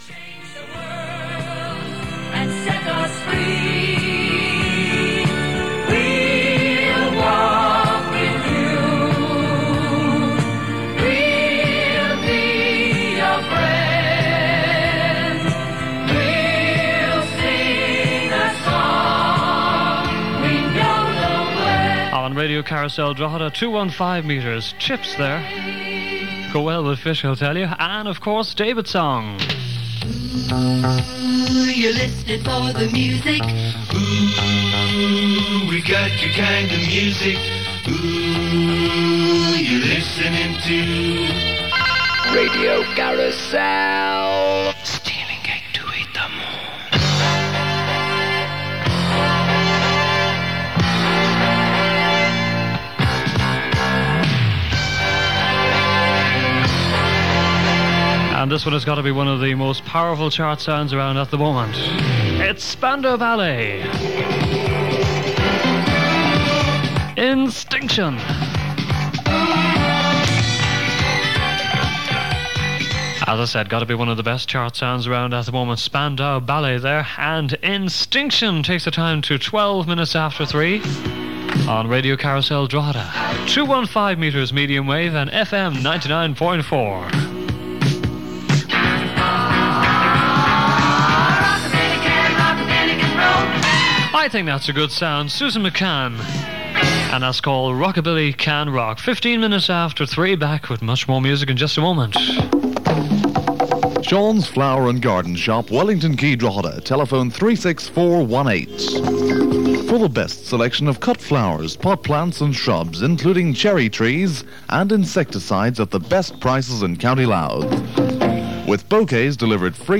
Radio Carousel Drogheda also broadcast on 1413 kHz, announcing 215 metres.